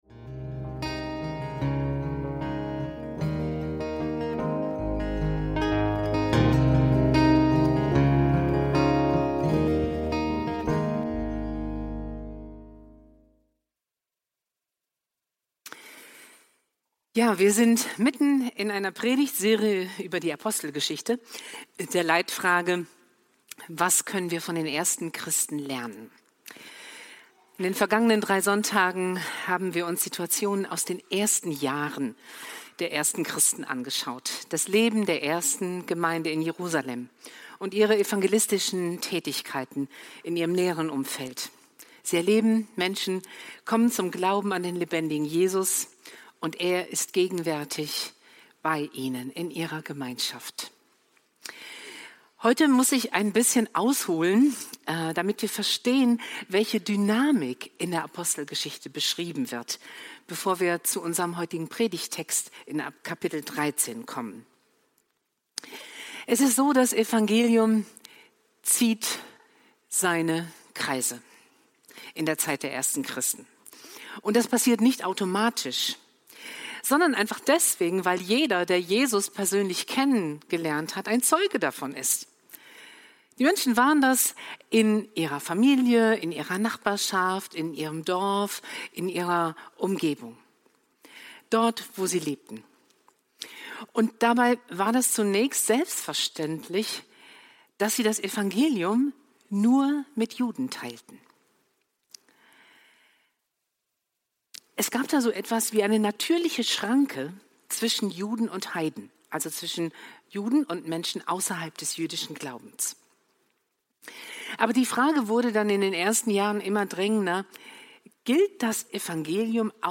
Bleiben oder Gehen – Gemeinde mit Mission – Predigt vom 15.02.2026